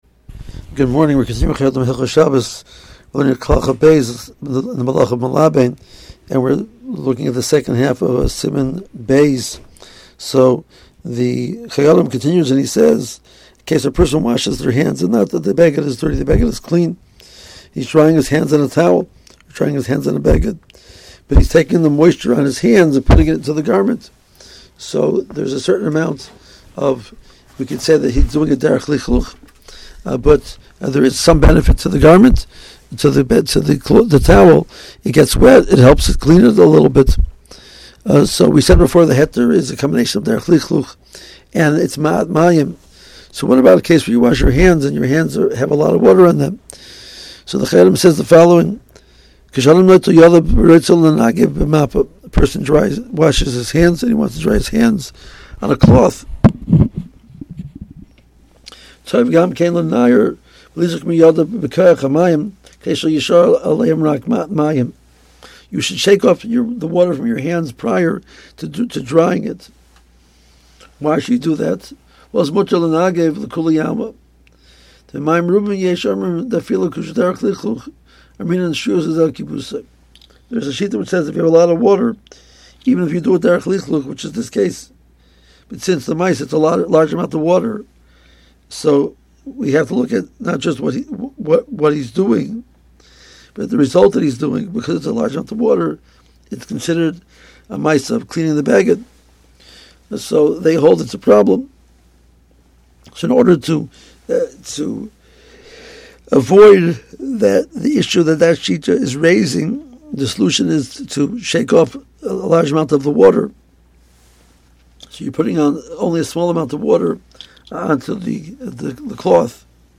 AUDIO SHIUR